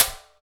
Index of /90_sSampleCDs/Roland L-CD701/PRC_Trash+Kitch/PRC_Kitch Tuned